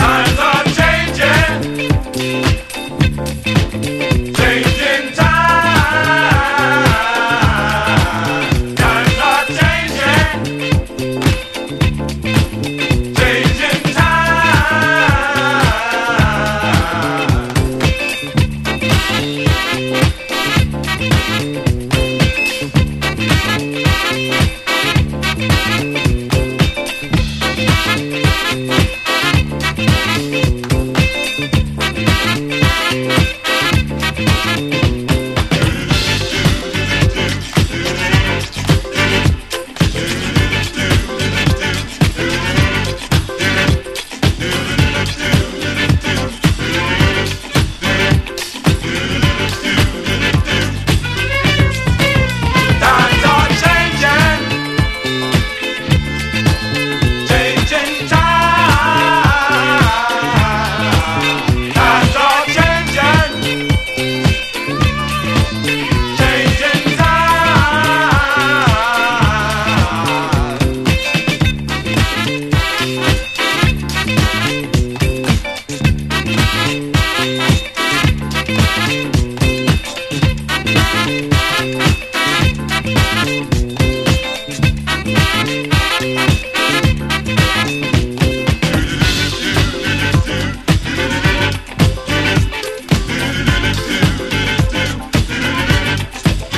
MODERN SOUL / SOURTHERN SOUL
マッスル・ショールズ録音のハートフル・レディ・モダンソウル！